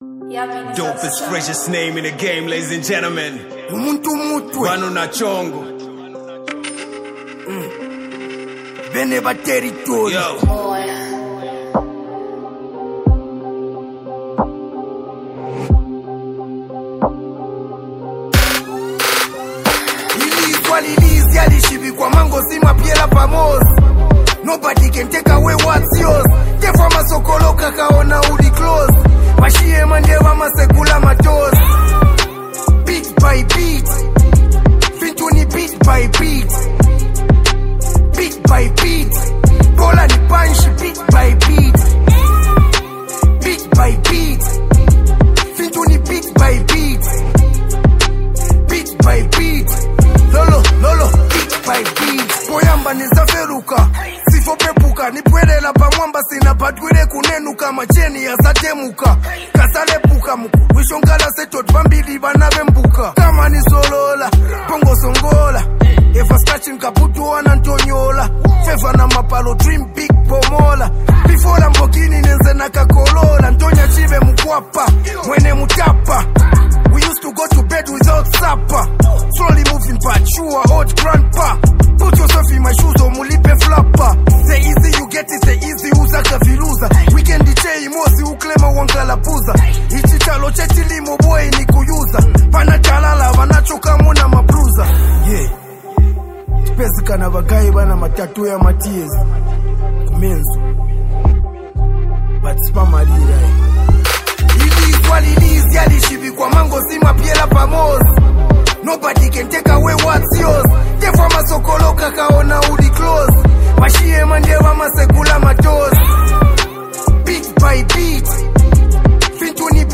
Zambian hip-hop
blends hard-hitting beats with motivational lyrics